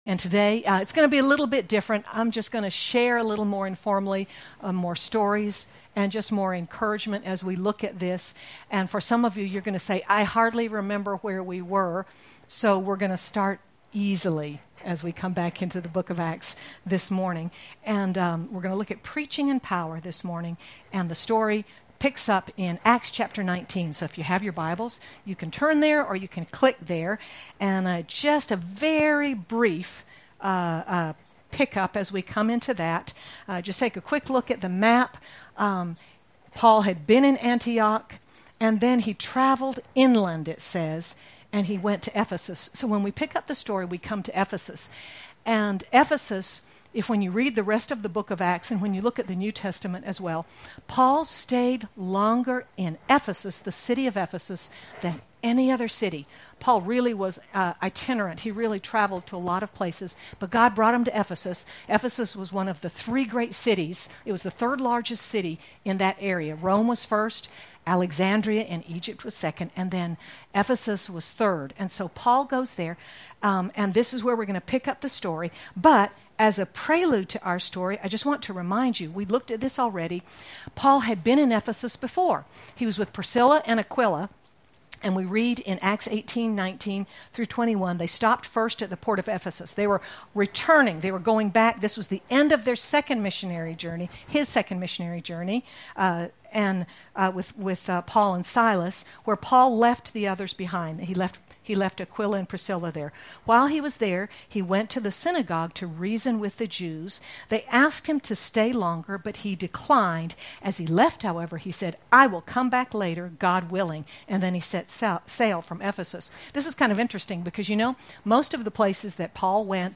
Sermon By